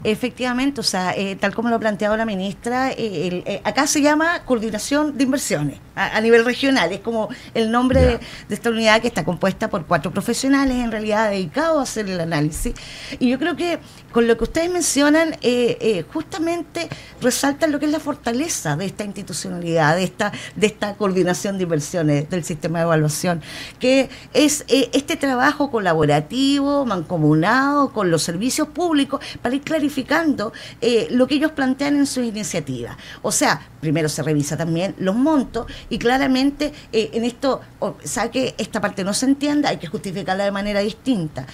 Por su parte, la seremi de Desarrollo Social en Tarapacá, Patricia Pérez, indicó a Radio Paulina que la iniciativa aún requiere antecedentes adicionales para poder avanzar hacia una recomendación favorable.